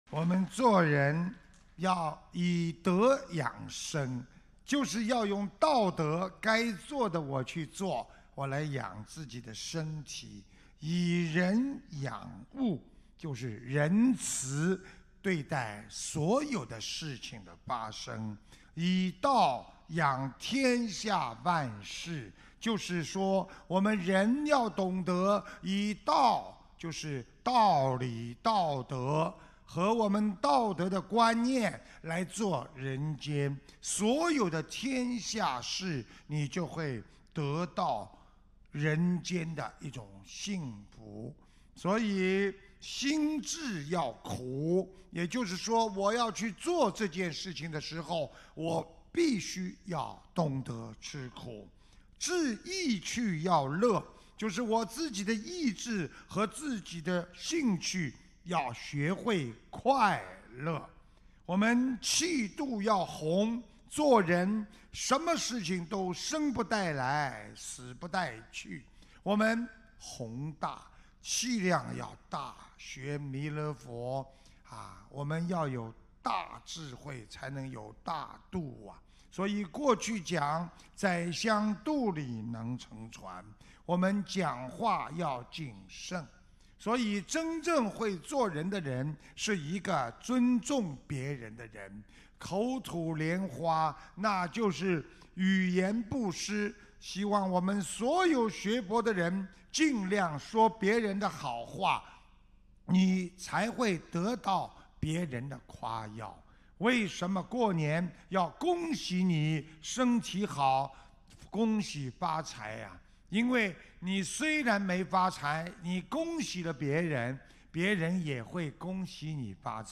目录：☞ 2016年03月_澳大利亚_布里斯班_开示集锦